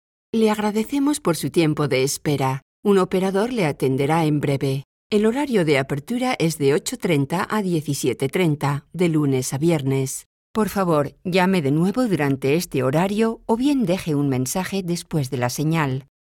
Sprecherin spanisch. Werbesprecherin. Sprecherin für Sprachkurse.
kastilisch
Sprechprobe: Sonstiges (Muttersprache):
Spanish female voice over talent.